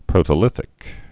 (prōtə-lĭthĭk)